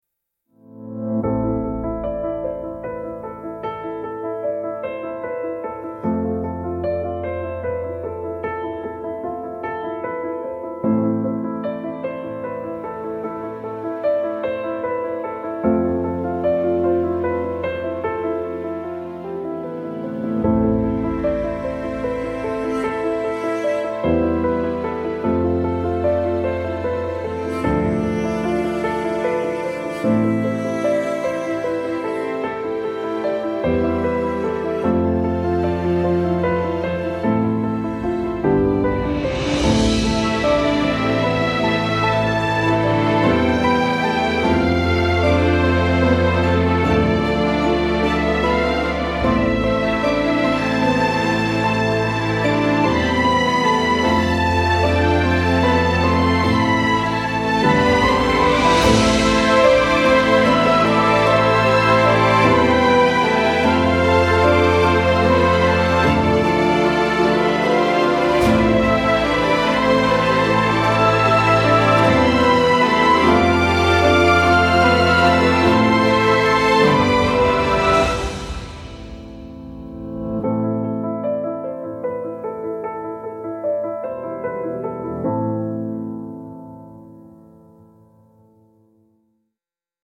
tender orchestral piece with warm golden tones and emotional depth